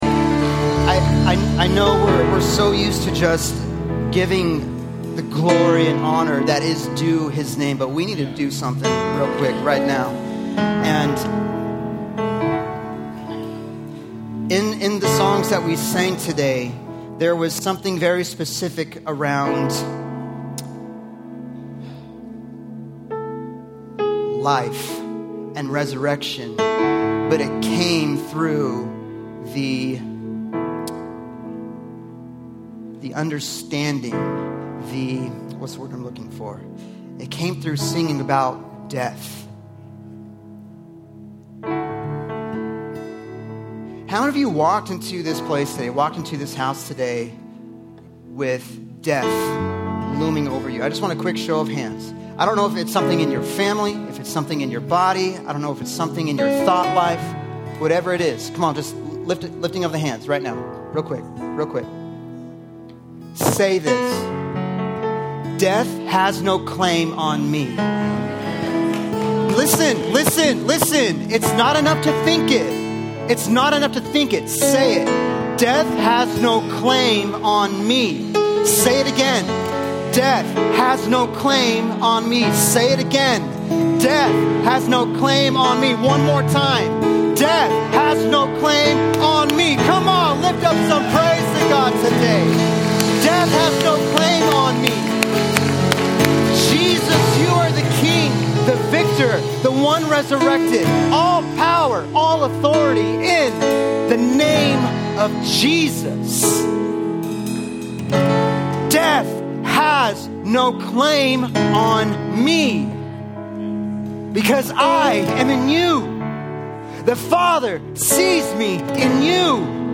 Sermon Series: MOMENTUM